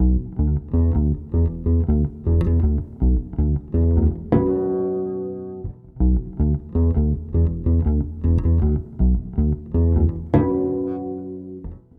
贝斯融合6
描述：适用于许多流派的无品类爵士贝斯的旋律
Tag: 80 bpm Fusion Loops Bass Guitar Loops 2.02 MB wav Key : E